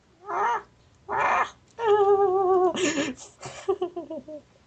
Screeching + Laughter